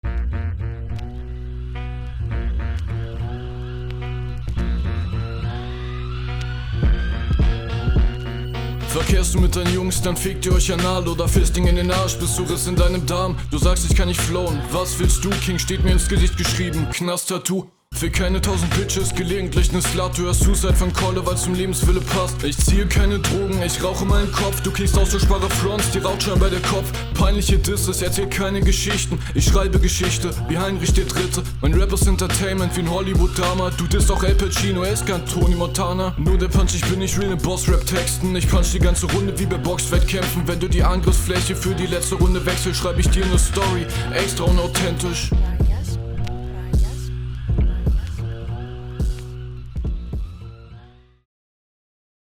Stimmlich viel besser als in der ersten Runde, kommt auf jeden cool auf den Beat …